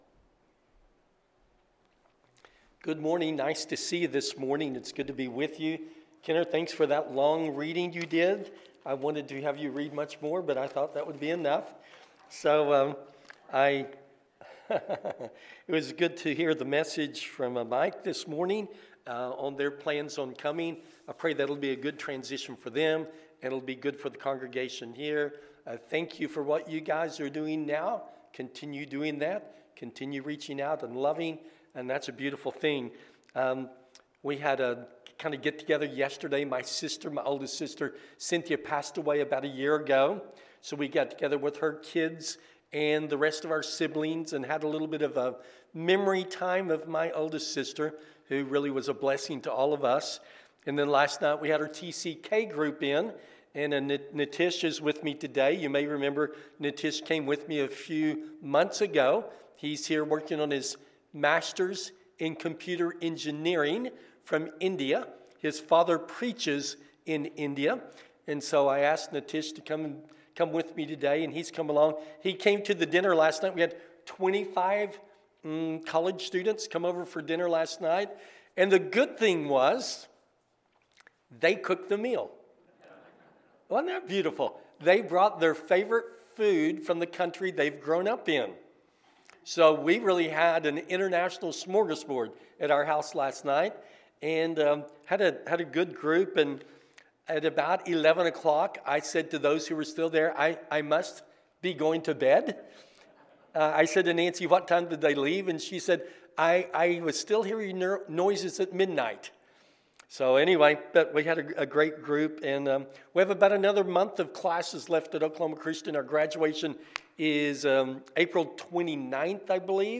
Serving Through the Fog – Sermon